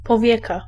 Ääntäminen
US Tuntematon aksentti: IPA : /ˈaɪlɪd/